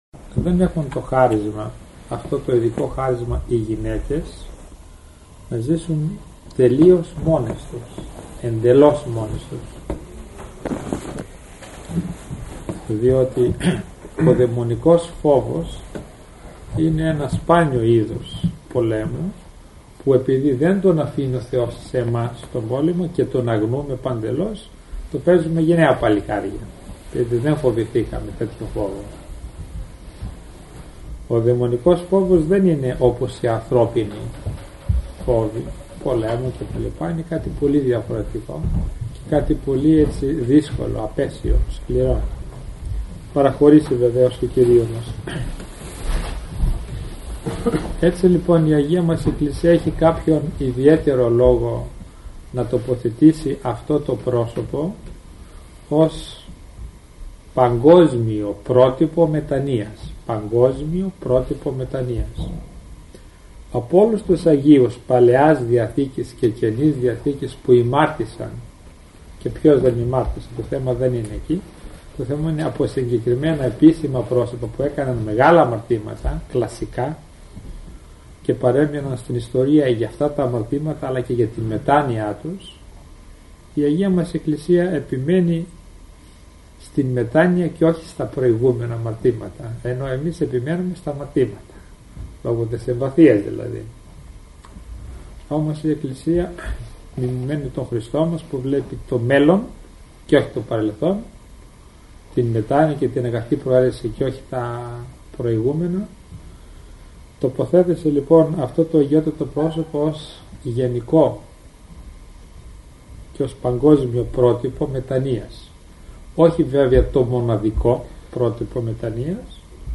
Ακολούθως σας παραθέτουμε ηχογραφημένη ομιλία του Πανοσ.